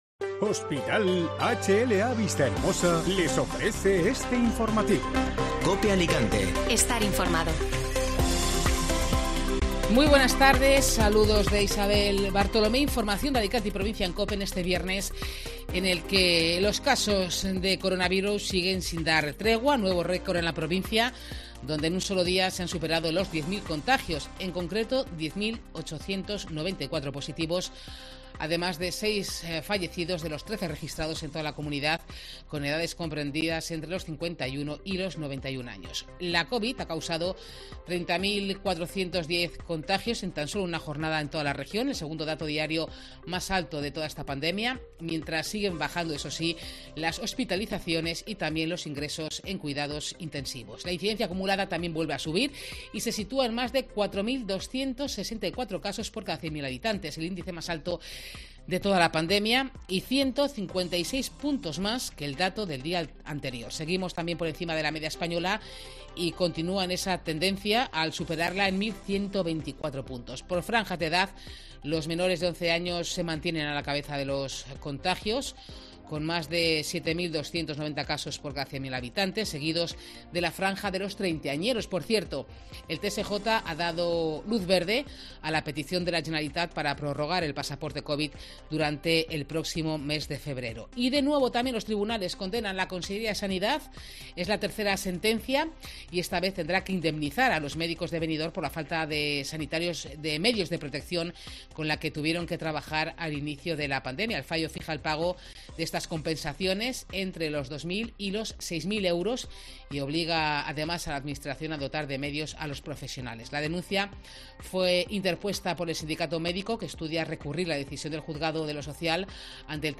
Informativo Mediodía COPE (Viernes 28 de enero)